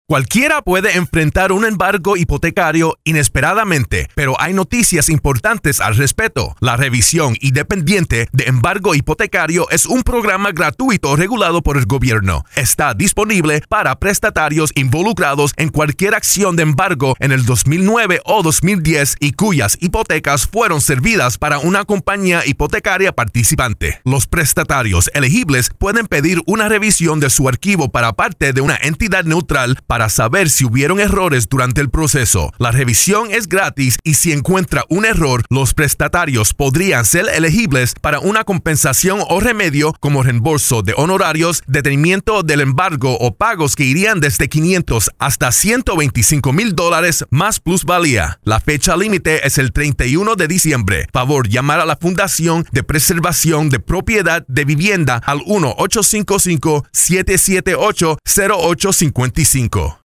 December 17, 2012Posted in: Audio News Release